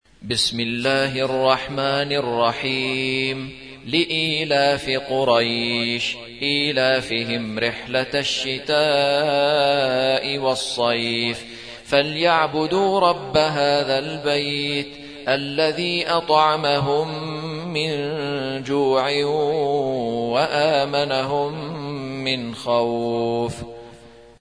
106. سورة قريش / القارئ